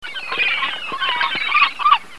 seagulls2.mp3